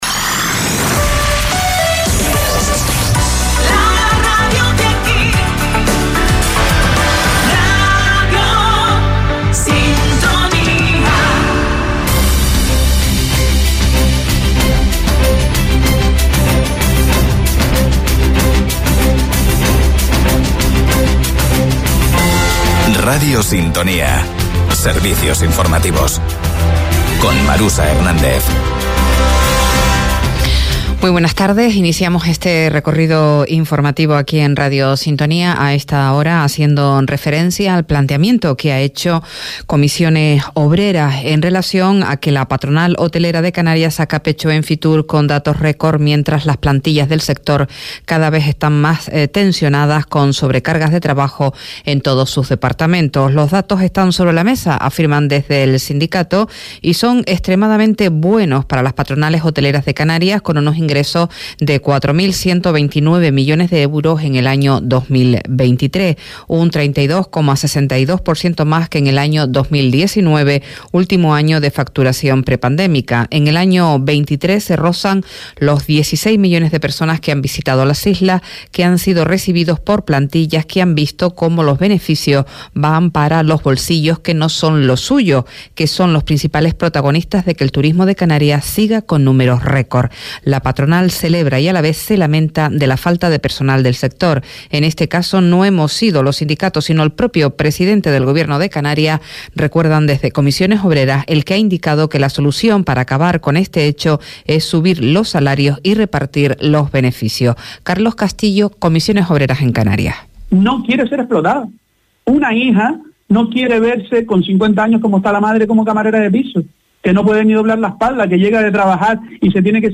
Informativos en Radio Sintonía - 02.02.24